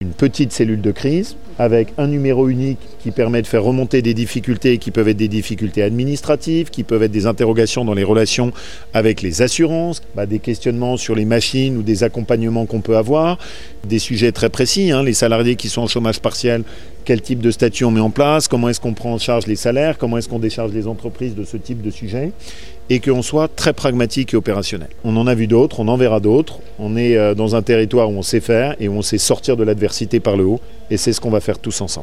Laurent Wauquiez, Président d’Auvergne-Rhône-Alpes: